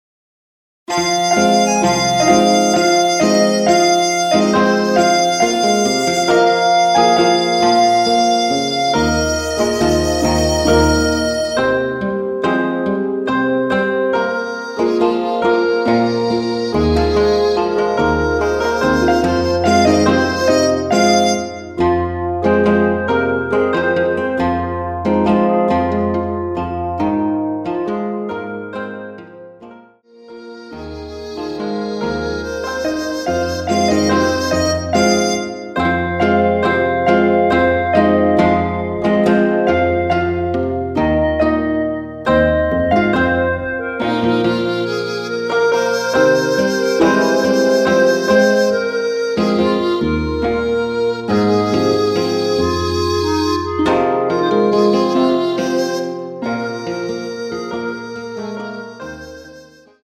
국악기 음원으로 제작 하였습니다.
남성 분이 부르실수 있는 멜로디 MR 입니다.(미리듣기 참조)
Fm
앞부분30초, 뒷부분30초씩 편집해서 올려 드리고 있습니다.
중간에 음이 끈어지고 다시 나오는 이유는